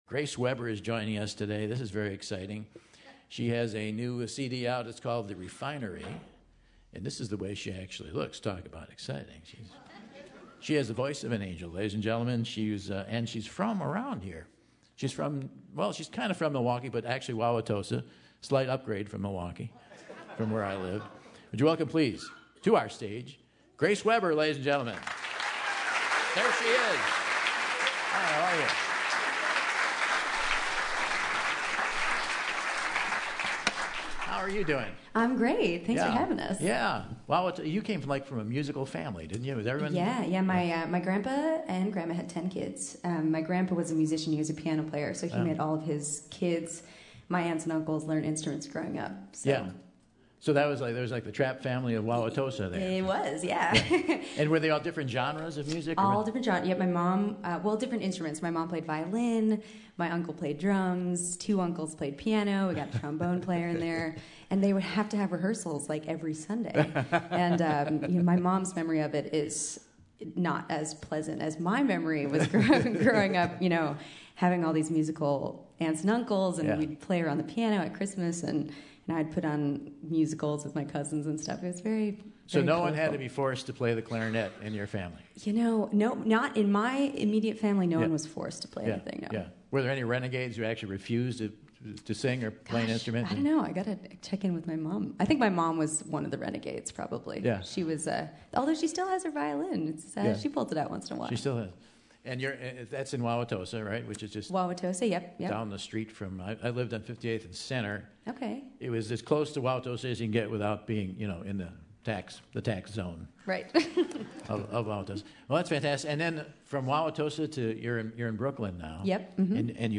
smoldering, soulful voice